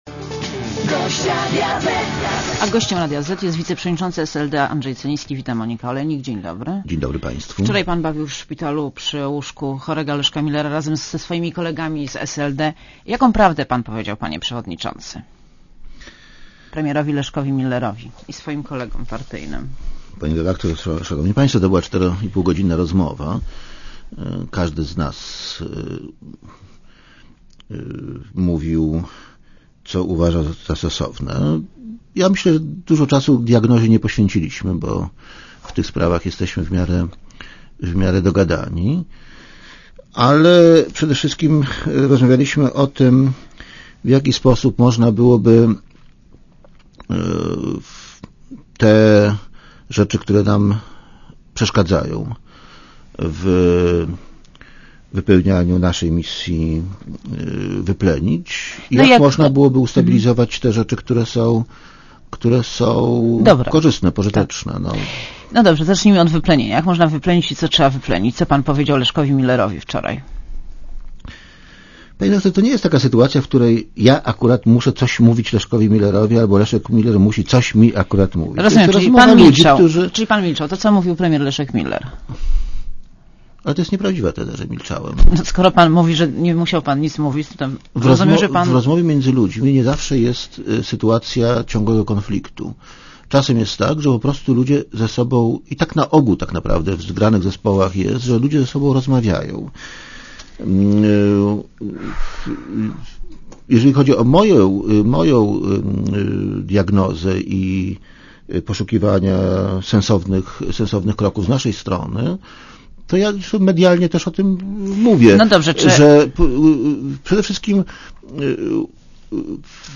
Gościem Radia Zet jest wiceprzewodniczący SLD Andrzej Celiński. Wita Monika Olejnik.